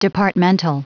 Prononciation du mot departmental en anglais (fichier audio)
Prononciation du mot : departmental